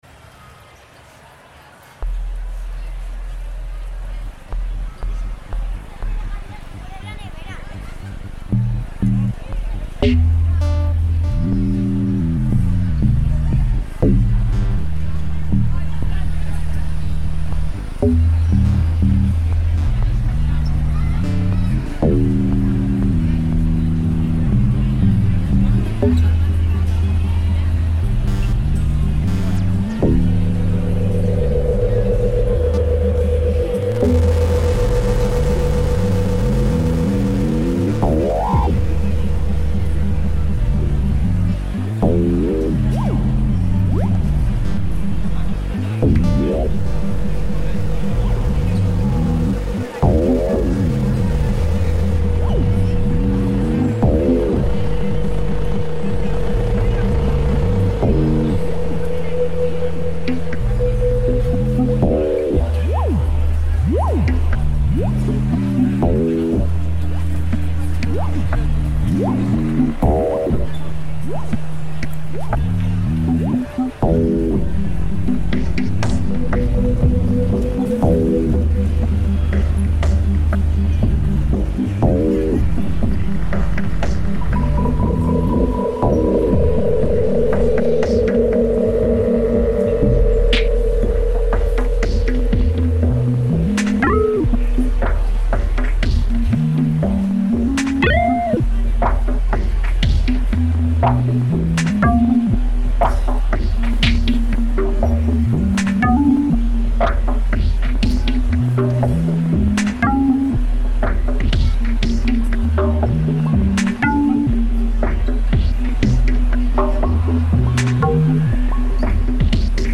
Valencia central market reimagined